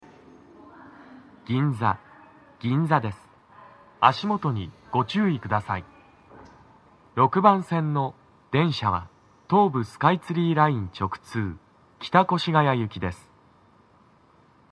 男声
到着放送1